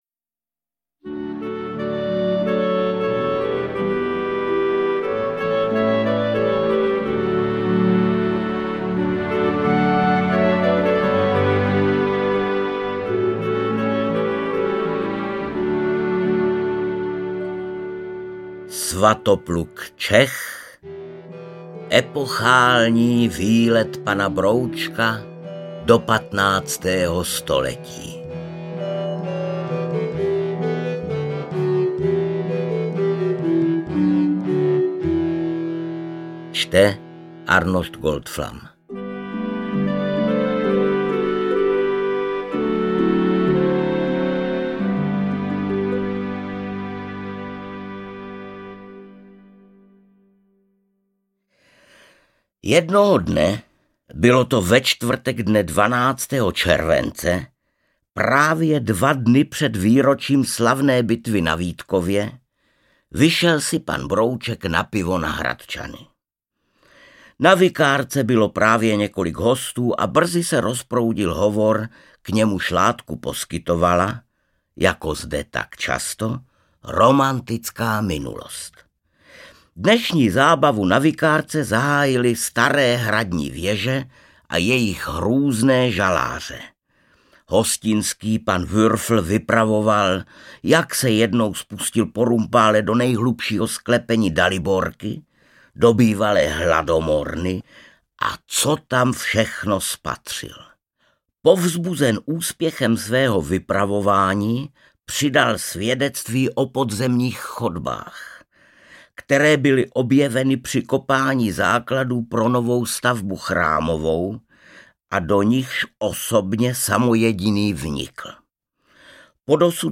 Interpret:  Arnošt Goldflam